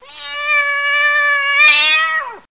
CATMEOW.mp3